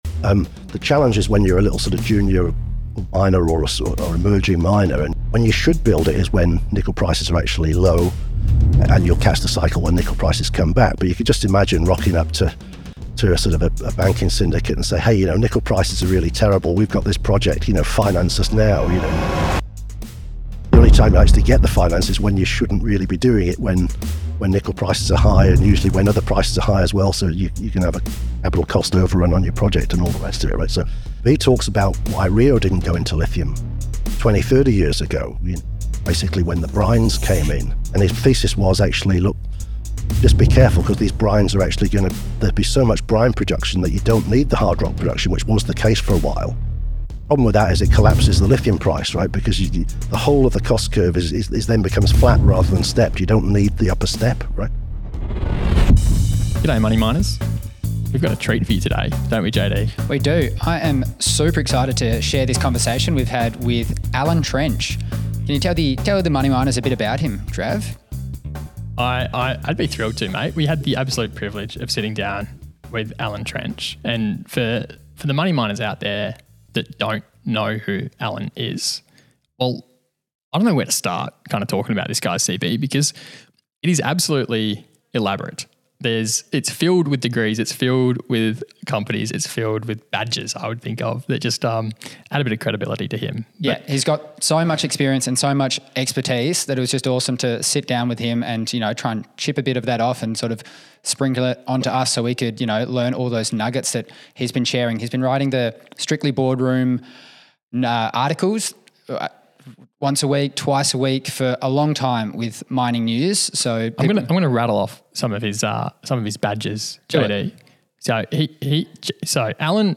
interviewing